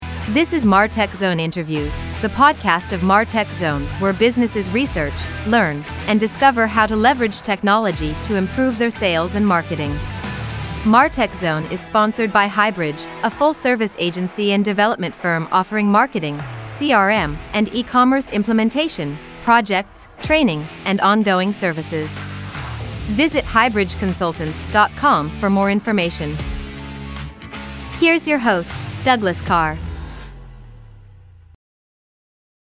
Murf: A Voice-To-Text Studio With Voices Powered By Artificial Intelligence (AI)
Within an hour I was able to record them, tweak the timing, and add background music.
I also noticed when I modified the emphasis, pitch, or speed, the voice didn’t sound as natural.
Podcast Intro